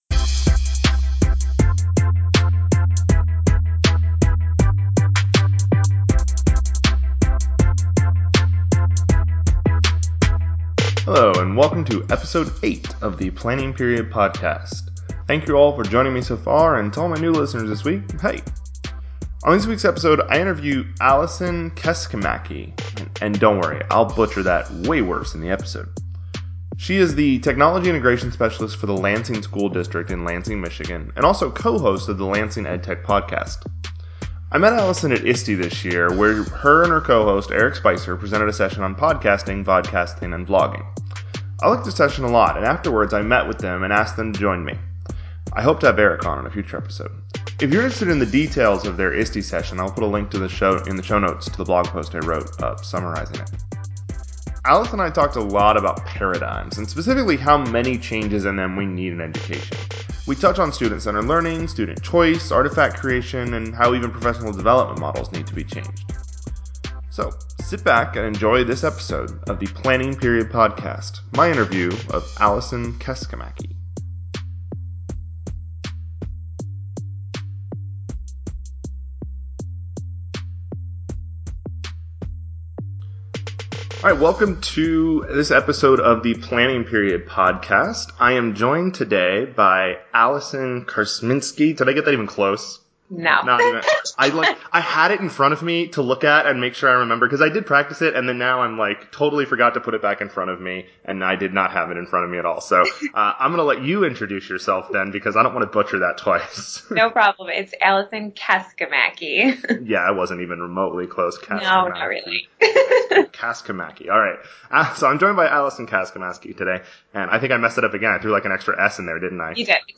Hello, and welcome to Episode 8 of the Planning Period Podcast.